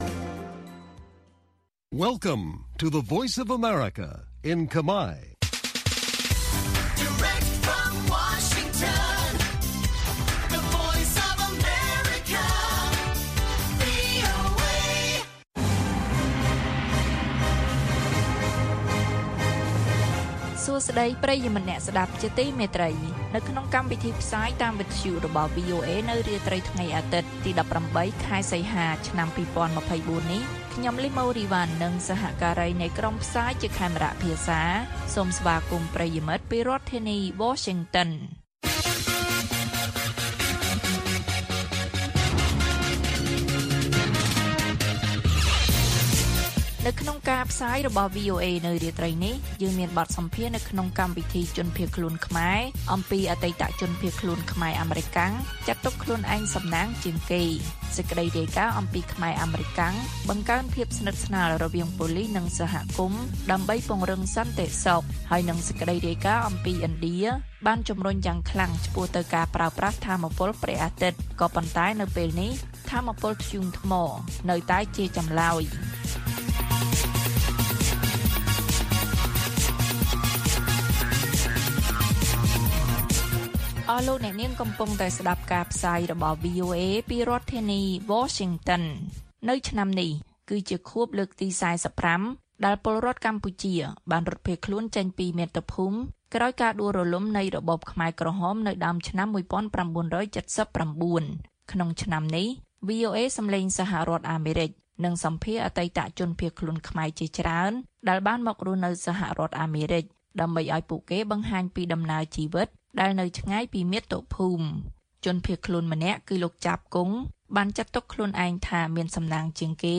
ព័ត៌មាននៅថ្ងៃនេះមានដូចជា បទសម្ភាសន៍នៅក្នុងកម្មវិធីជនភៀសខ្លួនខ្មែរ៖ អតីតជនភៀសខ្លួនខ្មែរអាមេរិកាំង ចាត់ទុកខ្លួនឯងសំណាងជាងគេ។ ខ្មែរអាមេរិកាំងបង្កើនភាពស្និទ្ធស្នាលរវាងប៉ូលិសនិងសហគមន៍ដើម្បីពង្រឹងសន្តិសុខ និងព័ត៌មានផ្សេងទៀត៕